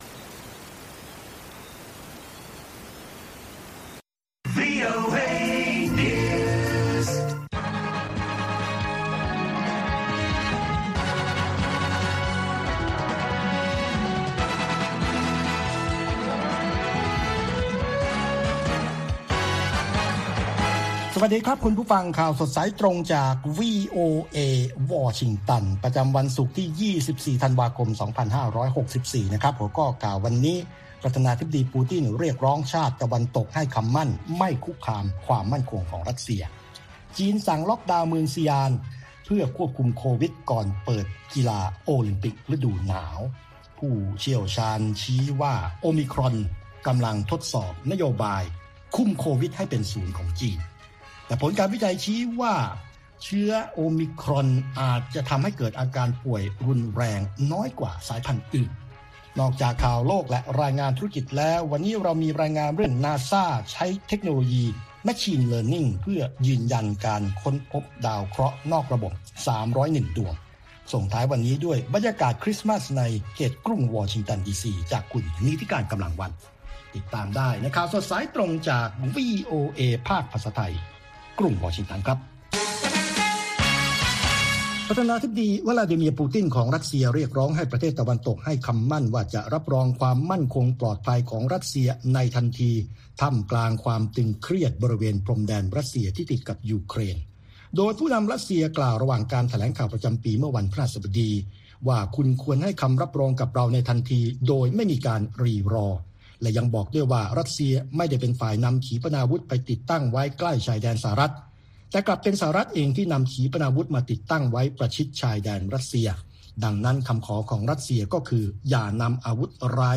ข่าวสดสายตรงจากวีโอเอ ภาคภาษาไทย 6:30 – 7:00 น. ประจำวันศุกร์ที่ 24 ธันวาคม 2564